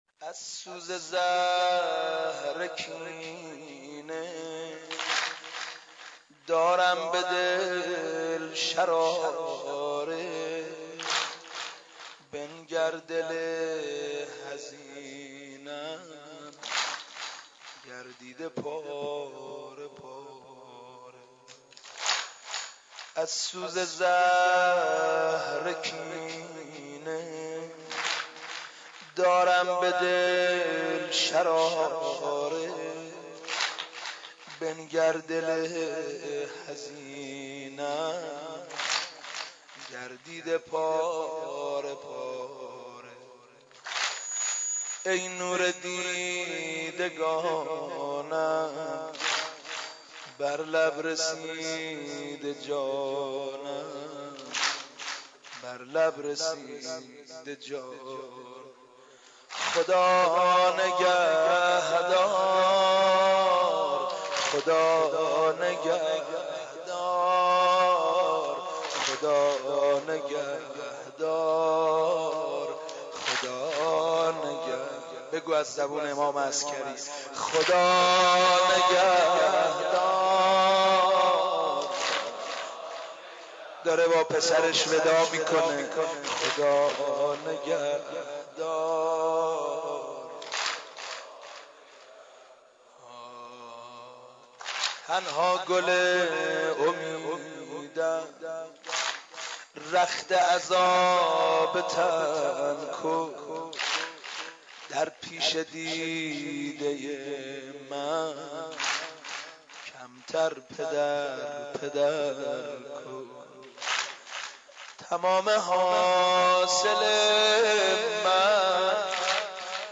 از سوز زهر کینه دارم به دل شراره | واحد | حاج سید مهدی میرداماد